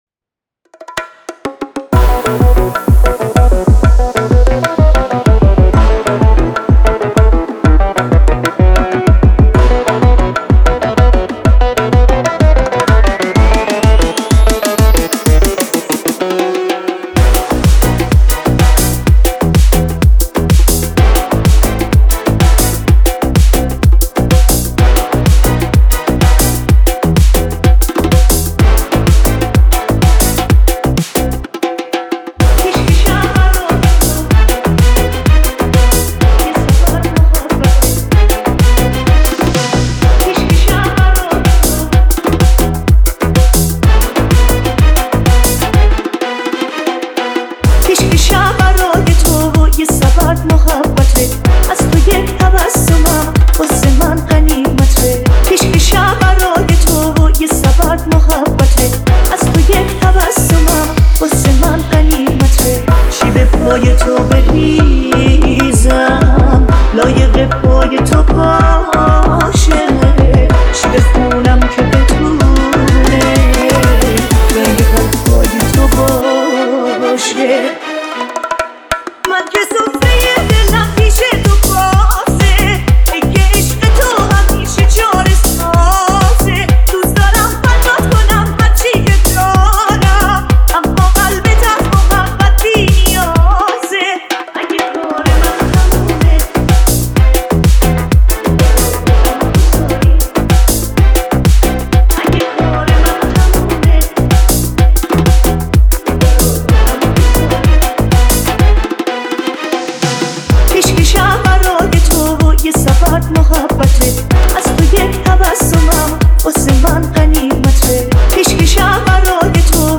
در سبک الکترونیک
مناسب رقص